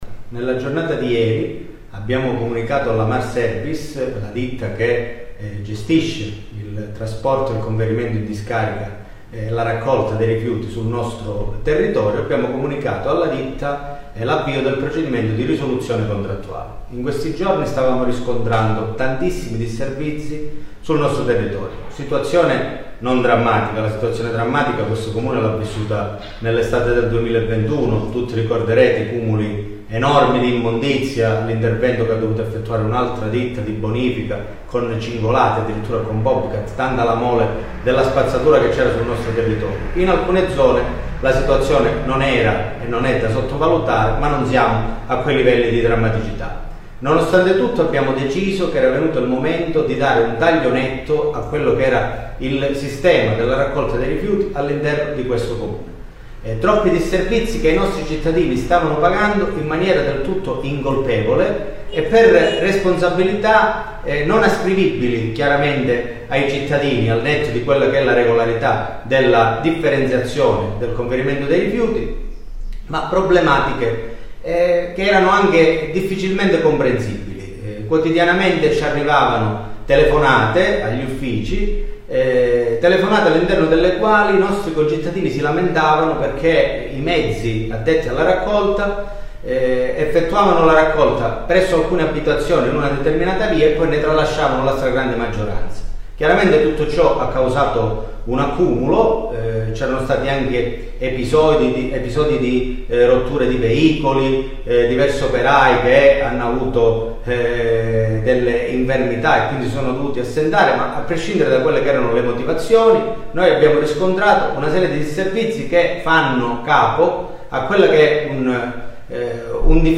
Il comune di Fuscaldo ha avviato la procedura di risoluzione contrattuale con la ditta che gestisce il servizio dei rifiuti. Ne ha dato comunicazione il sindaco Giacomo Middea con un video messaggio ai cittadini.
CLICCA E ASCOLTA L’INTERVENTO DEL SINDACO DI FUSCALDO GIACOMO MIDDEA